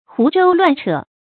胡謅亂扯 注音： ㄏㄨˊ ㄓㄡ ㄌㄨㄢˋ ㄔㄜˇ 讀音讀法： 意思解釋： 胡謅八扯。